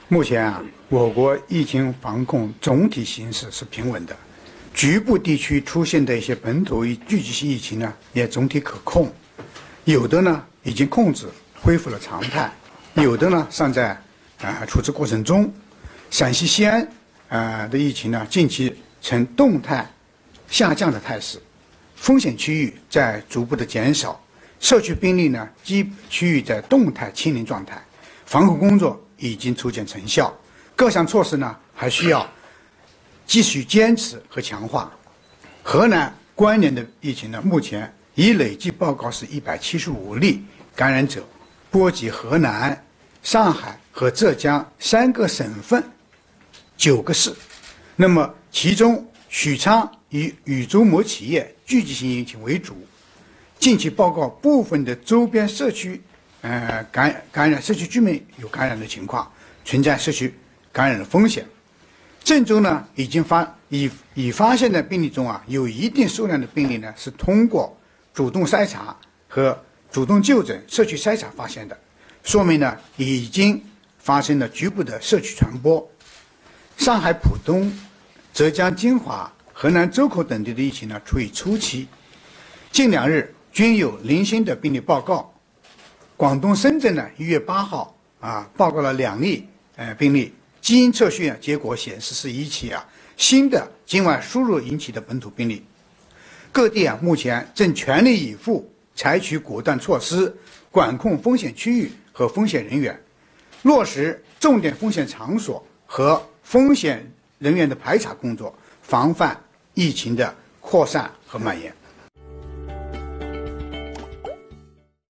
新闻发布会现场
国家卫生健康委疾控局一级巡视员贺青华